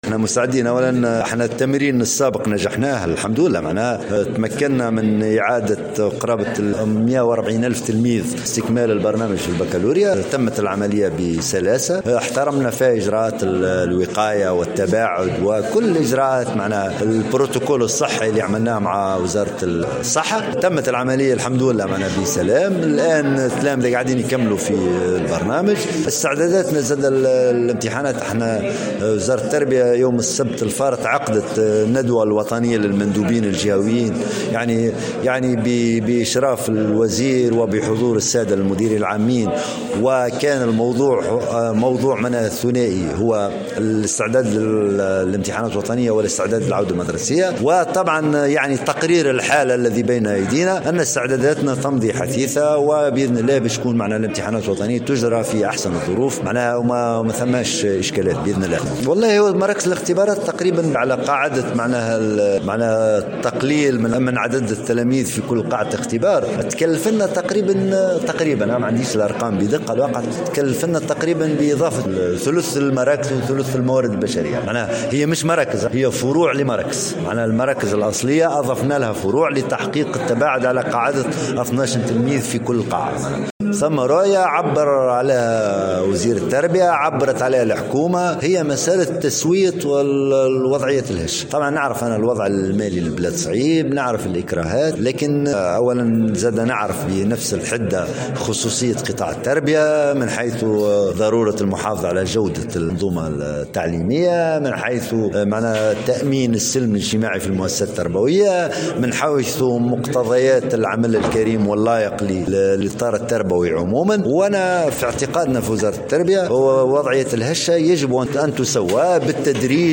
وأضاف الحامدي في تصريح للجوهرة أف أم أنه في إطار الإجراءات الإستثنائية التي استوجبتها أزمة كورونا ،تم إضافة عدد من مراكز الإمتحانات بمعدل 12 تلميذا في كل قاعة .